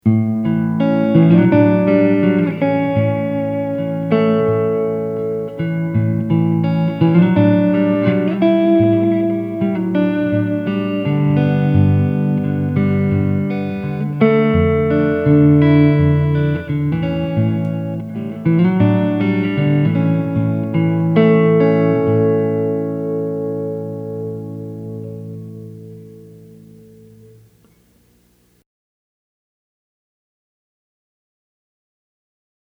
Please excuse the recording quality. I just recorded in an open room with no filtering. BTW, I used a Strat with a prototype Aracom RoxBox 18 Watt Amp with a Jensen 1 X10 speaker.
I prefer a more subtle reverb effect, but as you’ll hear, the RV-7 is crystal clear, and produces a very nice reverb effect.
rv7_room.mp3